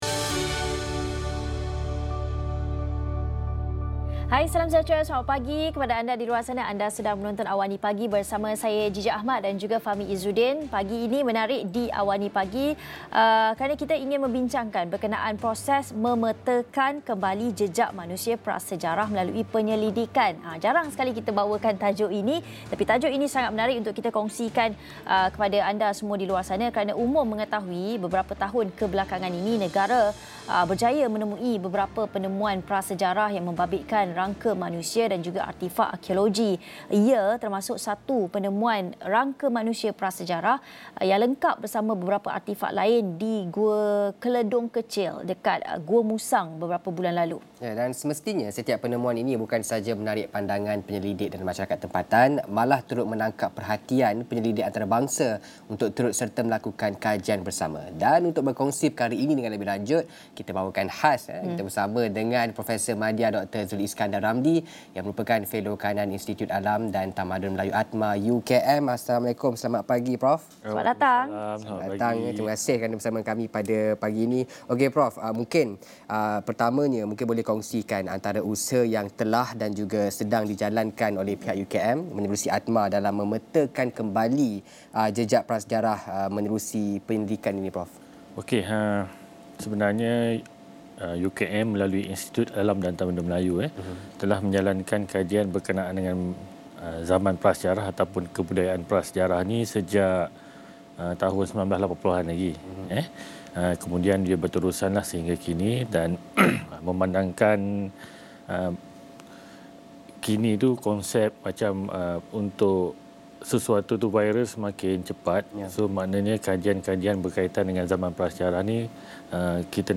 Diskusi 8.30 pagi bersama Felo Kanan